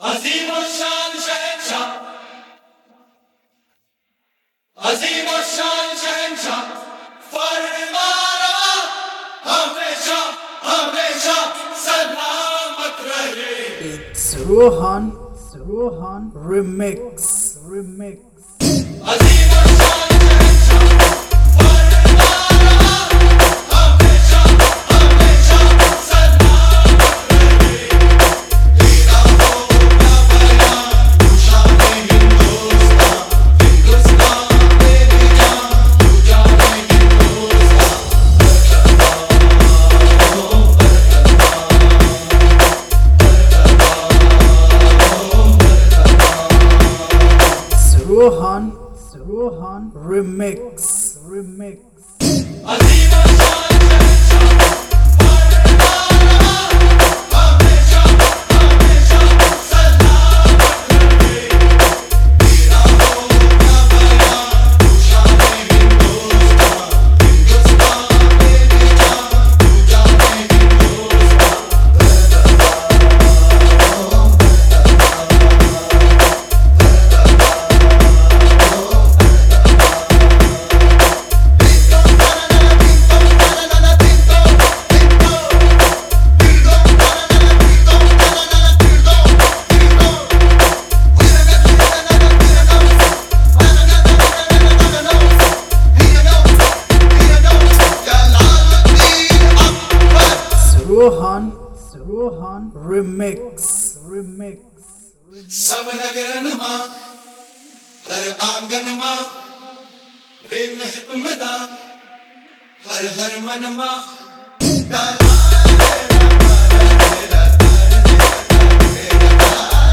Marathi Sound Check 2025